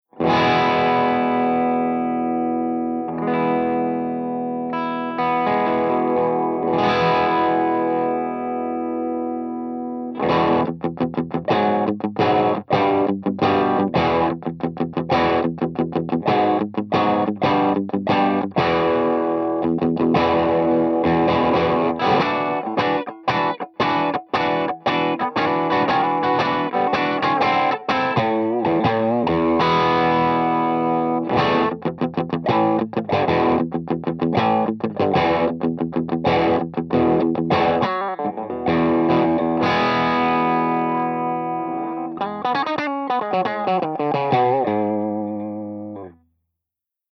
027_BUCKINGHAM_BASSMAN_P90.mp3